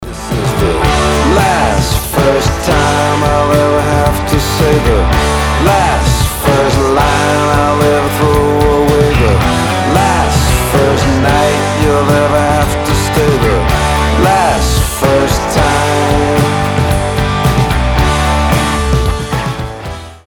мужской вокал
Alternative Rock
бодрые